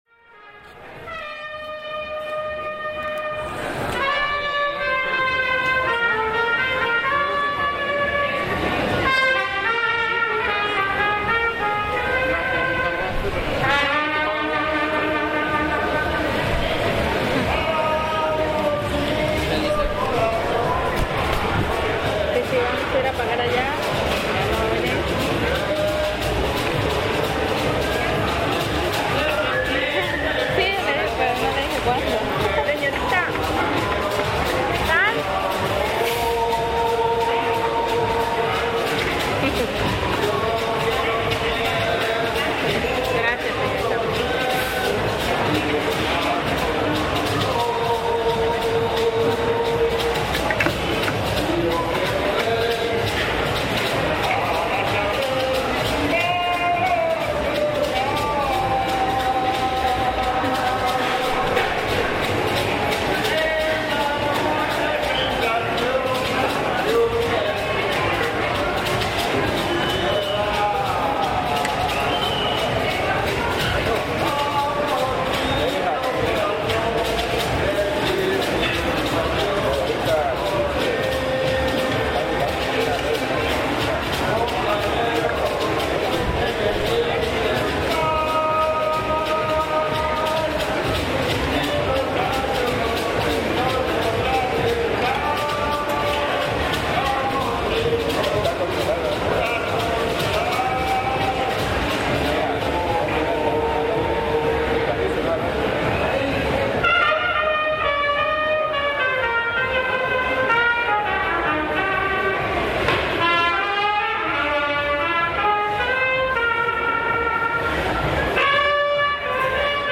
Músico en el mercado
En Tuxtla Gutierrez, Chiapas existen muchos mercados, cada uno tiene algo que lo identifica, este audio fue realizado en uno de los más grandes de esta ciudad, "El mercado de los Ancianos".
Las mesas ocupan el centro del lugar y es donde músicos amenizan a los comensales a cambio de unas monedas.